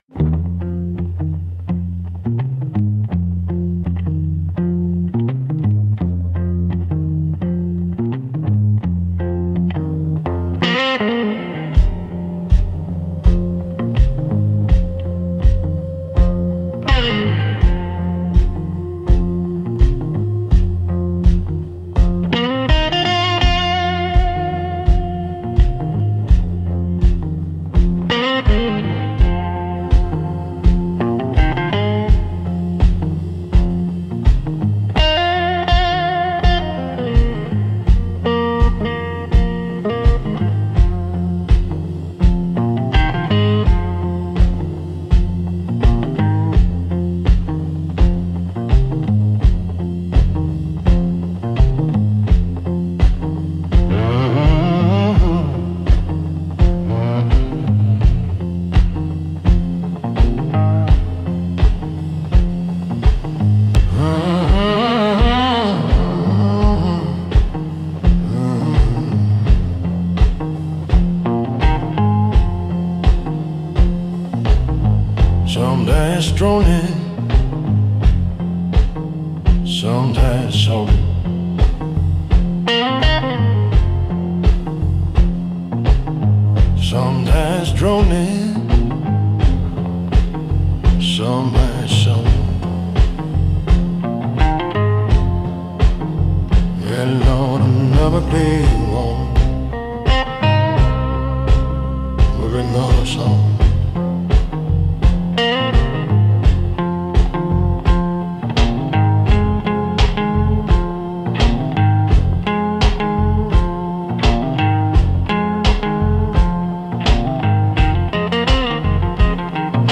Instrumental - Dust Devil Lullaby